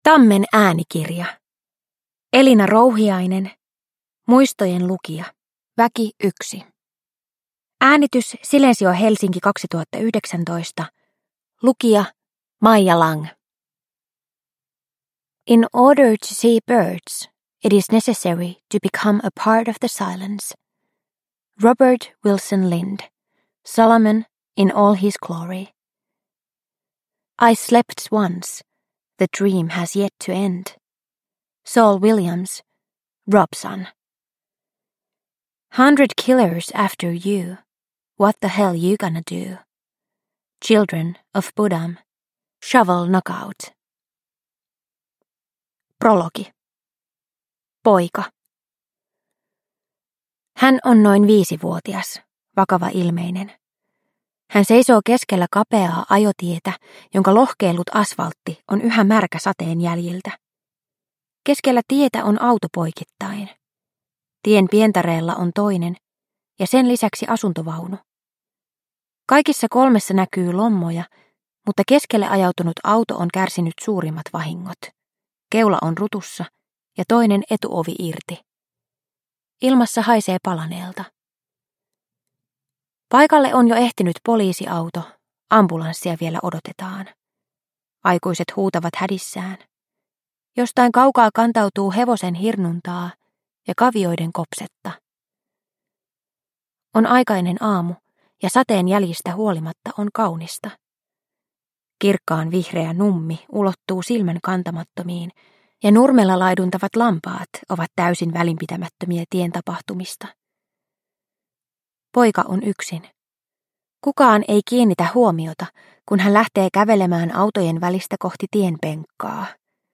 Muistojenlukija – Ljudbok – Laddas ner